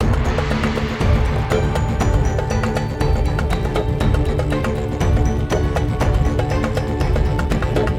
total_win_loop.wav